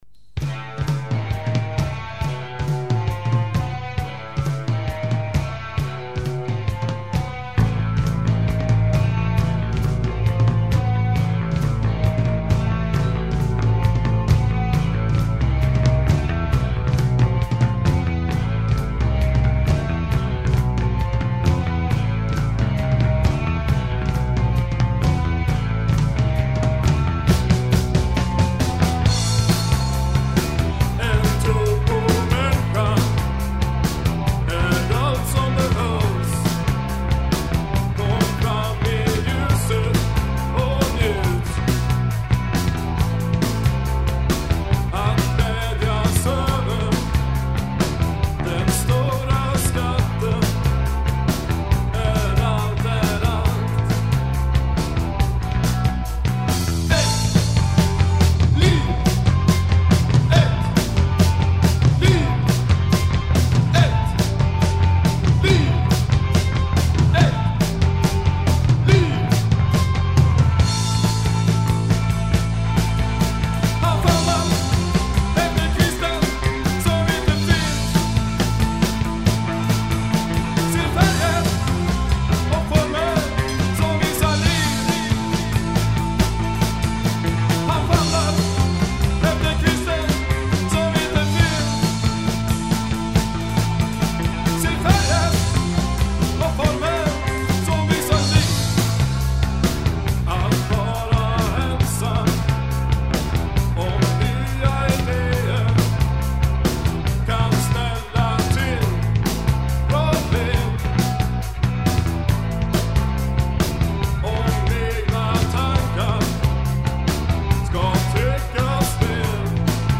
Bass
Guitar
Voice
Drums